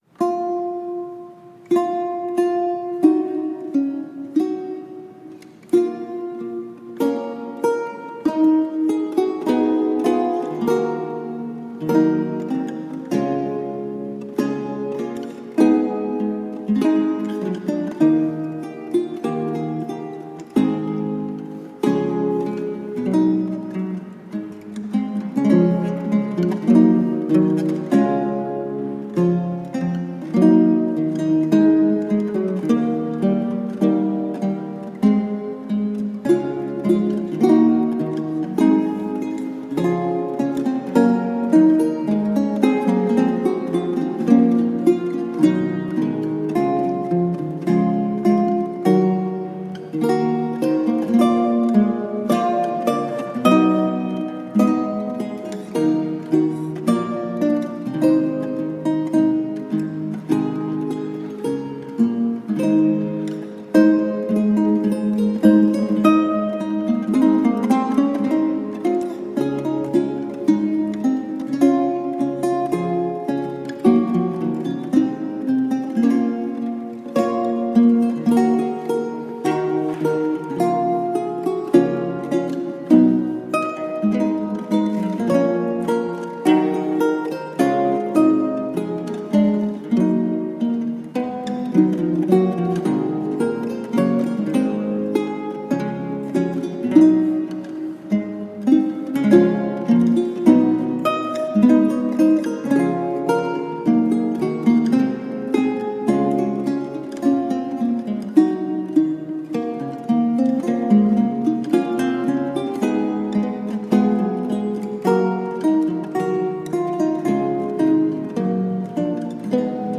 Spanish renaissance vihuela duets.